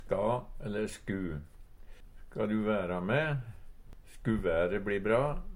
ska -sku - Numedalsmål (en-US)